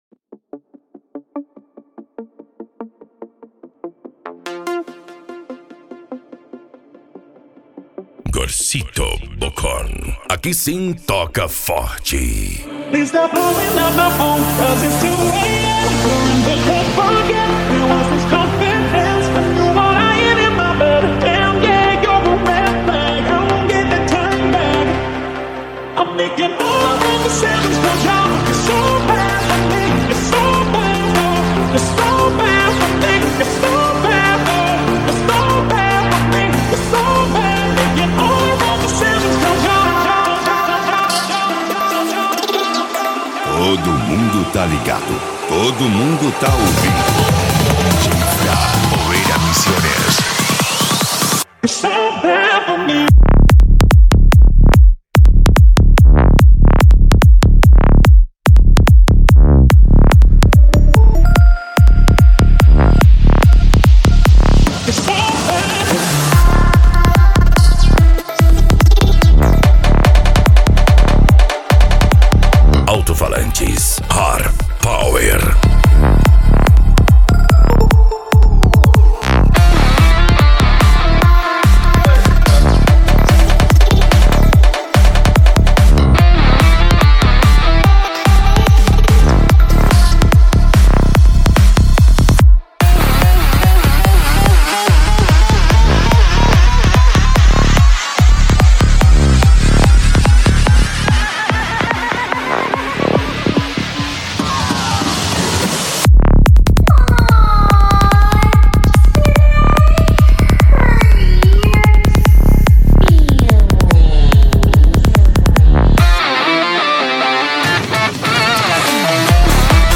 Remix
Bass